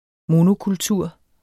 monokultur substantiv, fælleskøn Bøjning -en, -er, -erne Udtale [ ˈmono- ] Betydninger 1.